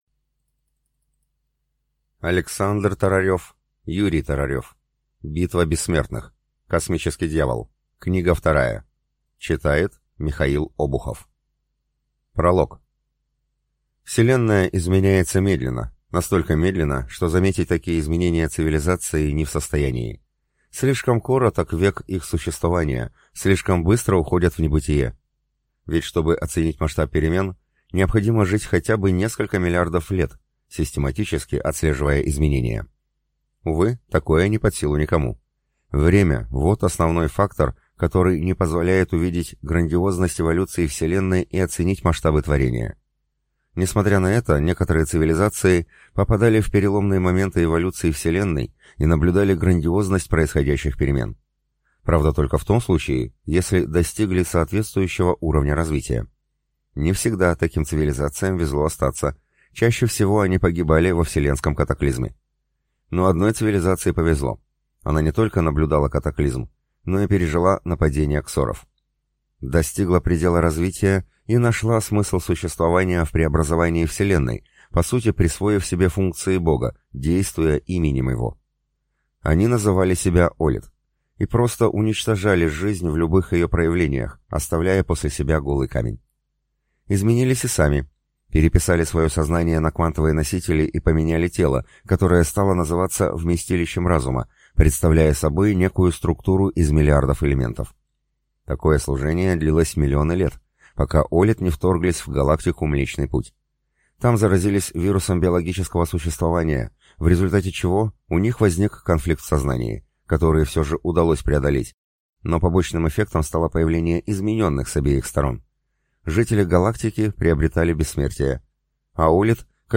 Аудиокнига Битва бессмертных. Космический дьявол. Книга вторая | Библиотека аудиокниг